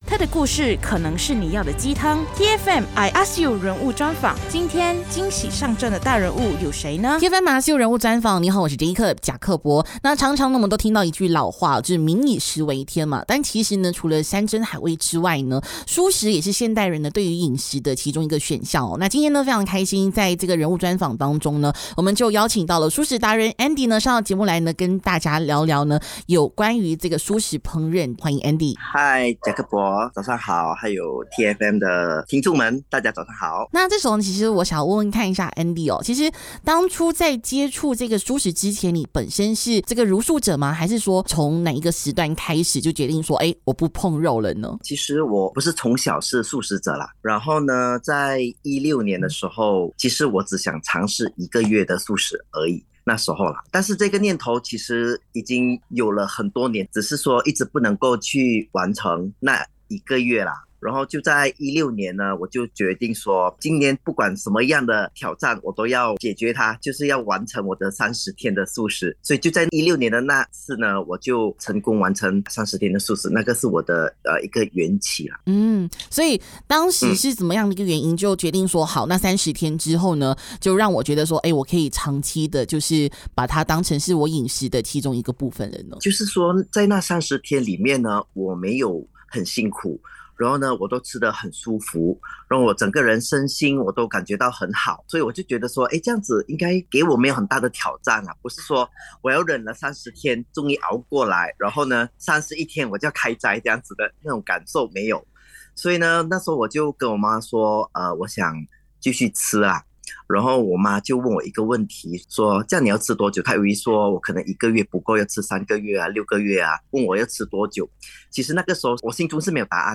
人物专访